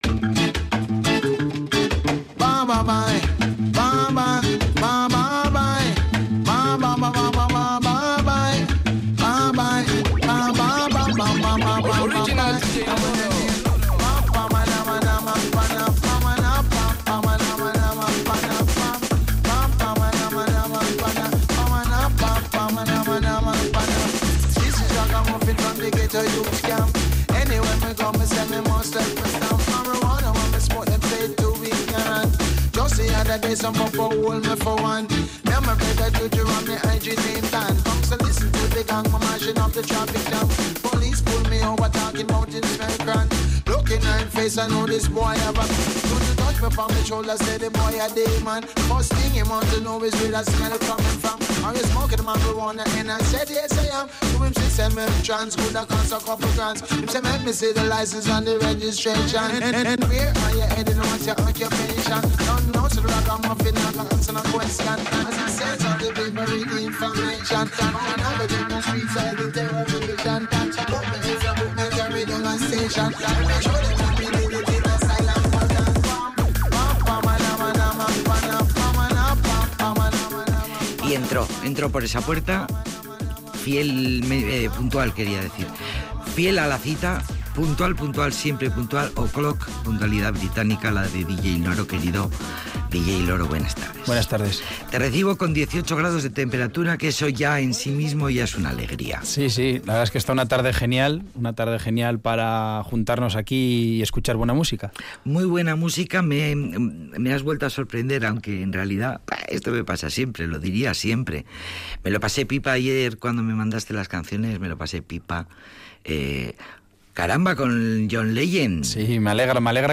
Un soulman brillante
uno de los mejores discos de música negra.